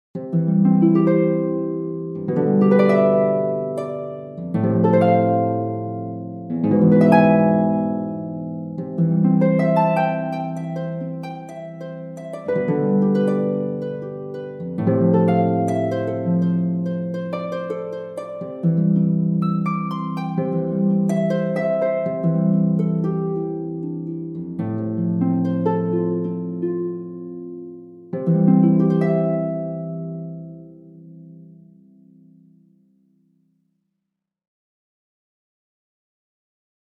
Harfa-fragment.mp3